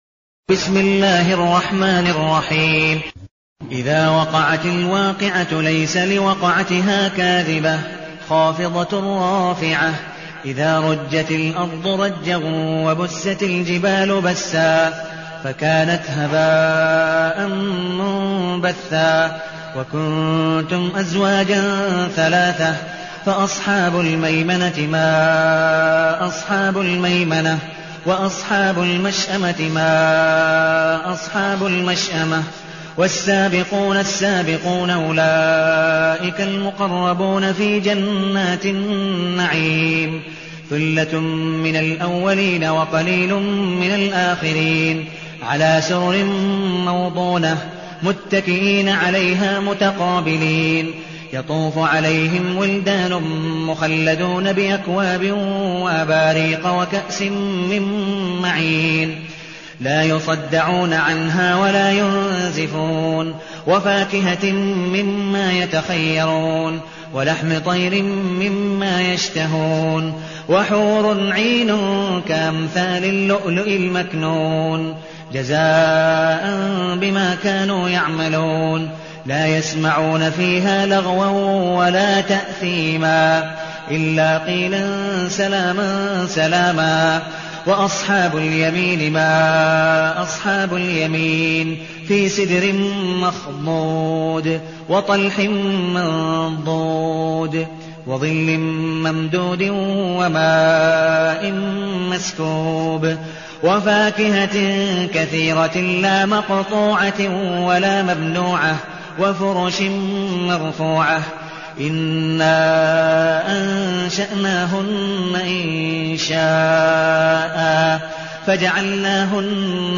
المكان: المسجد النبوي الشيخ: عبدالودود بن مقبول حنيف عبدالودود بن مقبول حنيف الواقعة